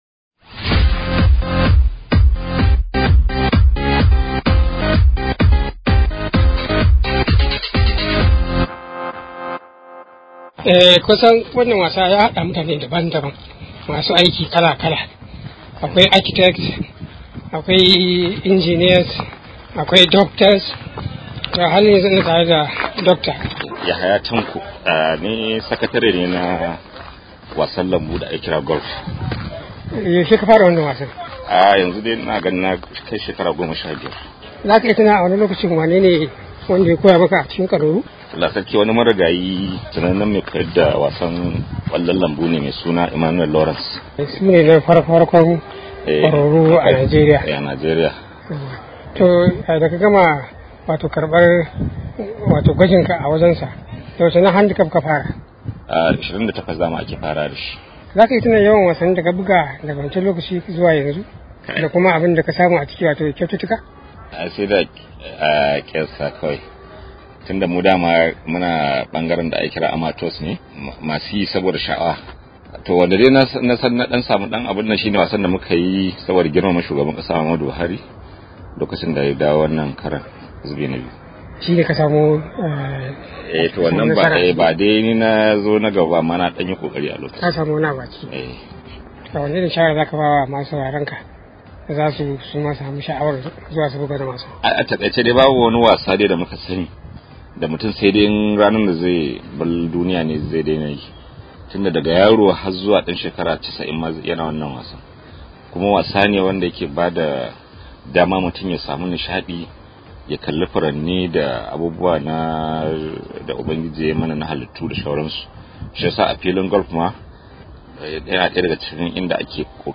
Daga filin kwallon Golf na Kano Club